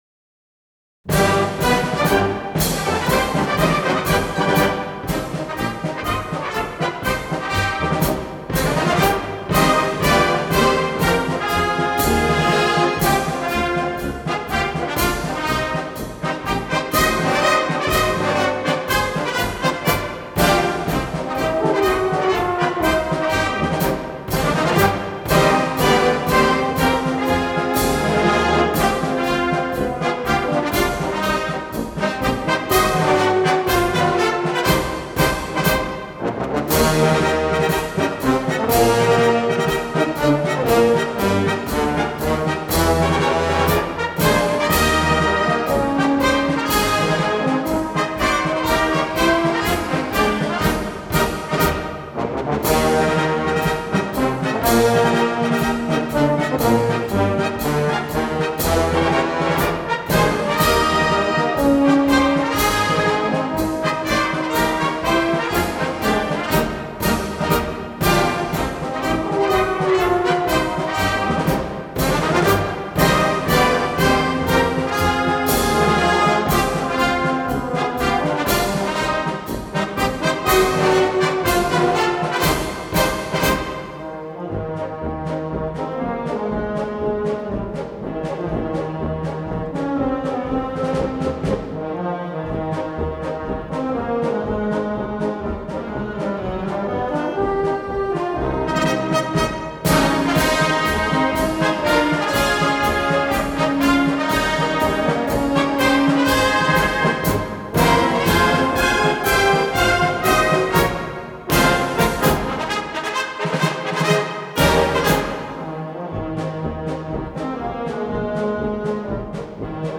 Главная / Computer & mobile / Мелодии / Марши